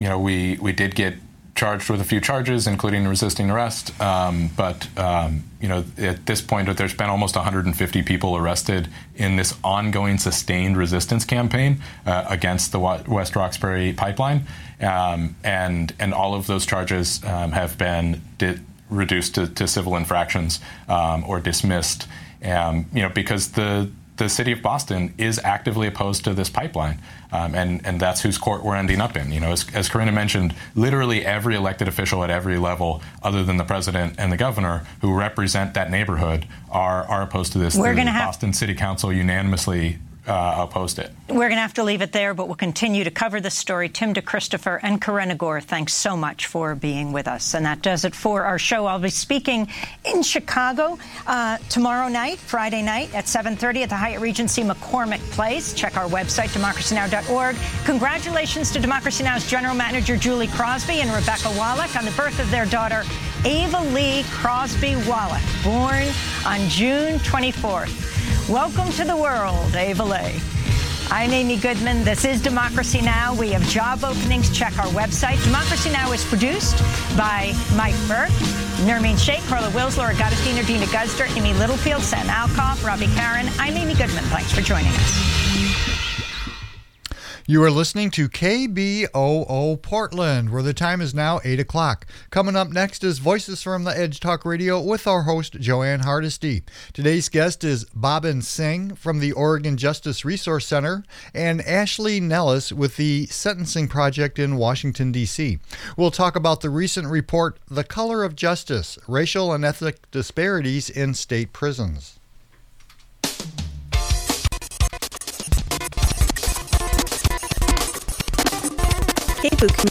Progressive talk radio from a grassroots perspective
Download audio file Host Jo Ann Hardesty interviews representatives from OPAL about their low-income fare campaign.